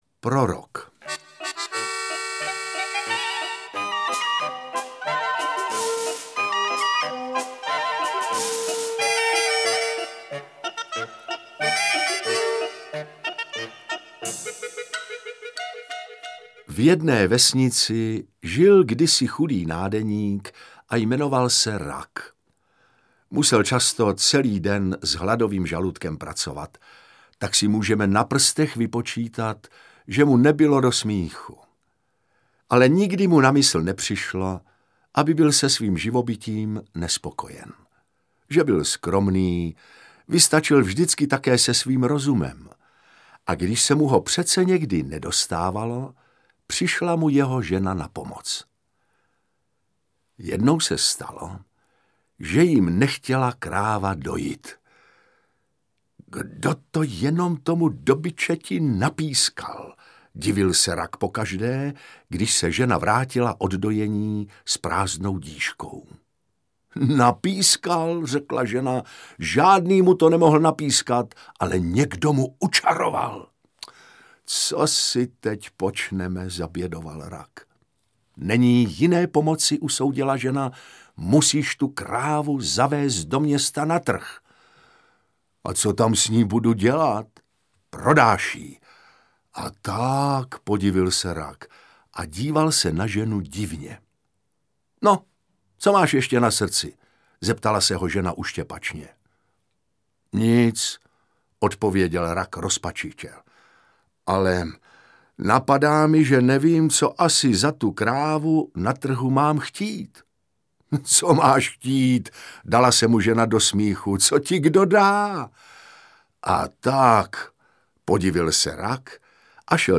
Interpret:  Otakar Brousek st.
V novém kompilačních titulu určeném pouze pro digitální obchody spojuje pohádky Prorok a O třech zakletých knížatech krásný a bohatý jazyk a také osoba vypravěče - Otakara Brouska st. Pohádky určené starším dětem provází hudba Miloše Vacka.
Lidová pohádka
AudioKniha ke stažení, 2 x mp3, délka 1 hod. 14 min., velikost 67,9 MB, česky